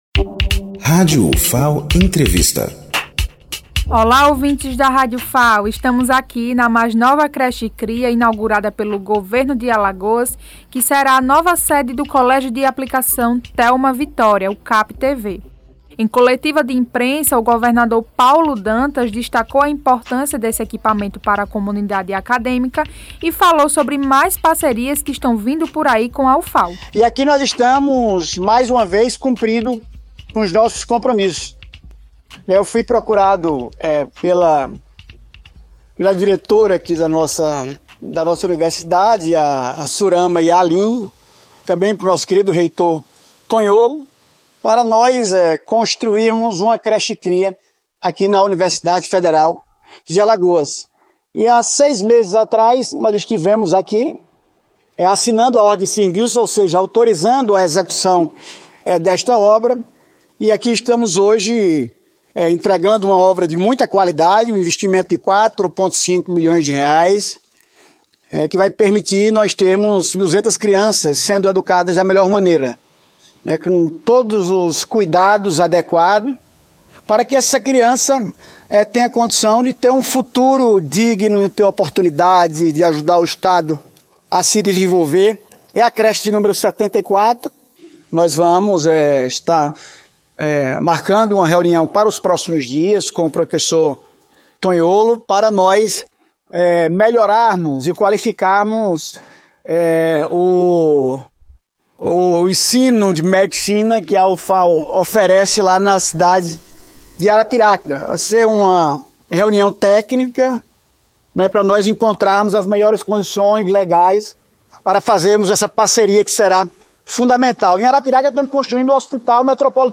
Inauguração da nova sede do Captv
Coletiva de imprensa com Paulo Dantas, governador do estado de Alagoas
Em coletiva de imprensa, o governador Paulo Dantas destacou a importância desse equipamento para a comunidade acadêmica e falou sobre mais parcerias que estão vindo por aí com a Ufal.